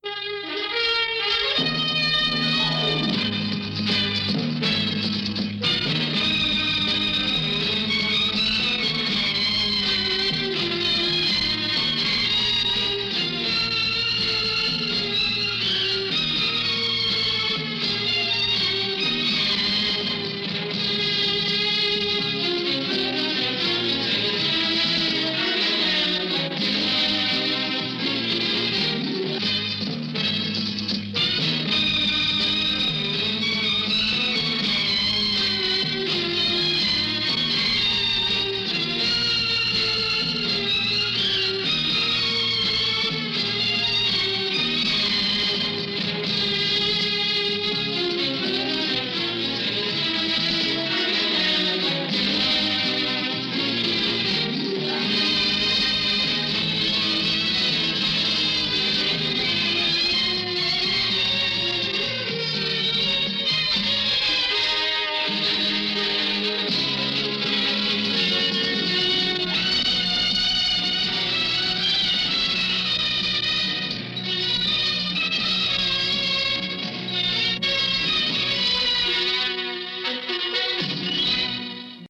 Música Instrumental